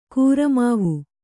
♪ kūramāv'u